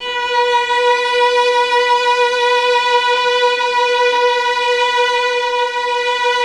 MELLOTRON .9.wav